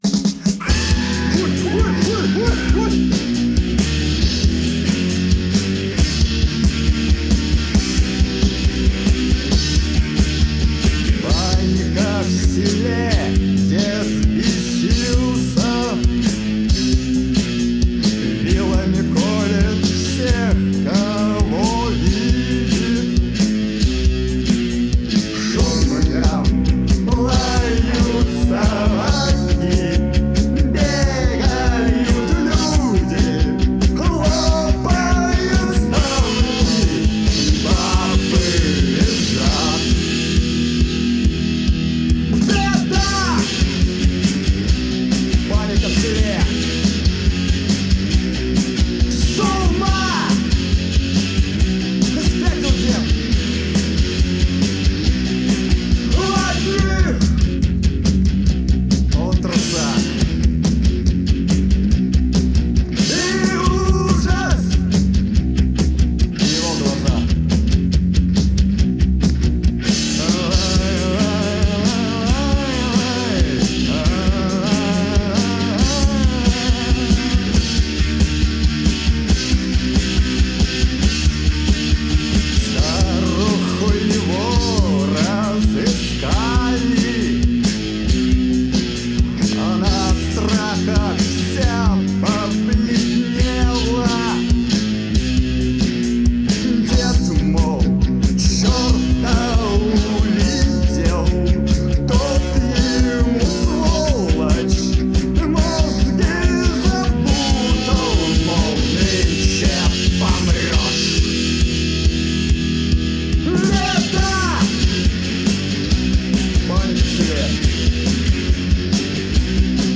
Жанр: Punk